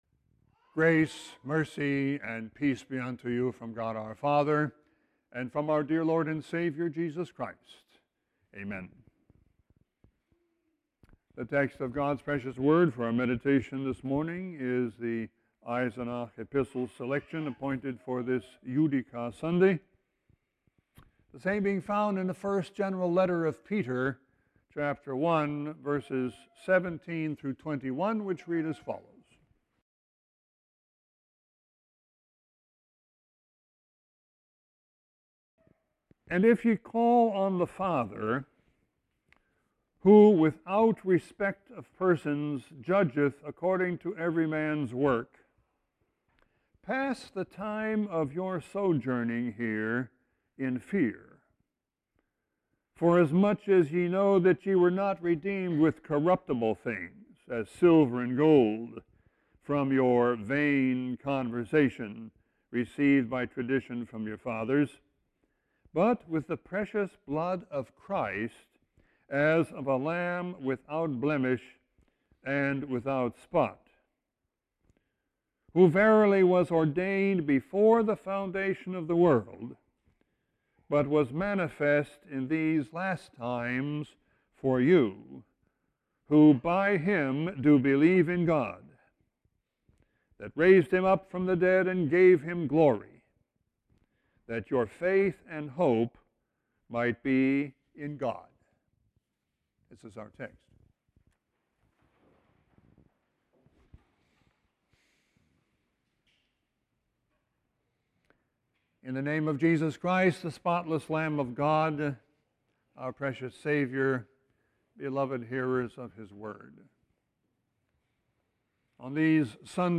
Sermon 3-13-16.mp3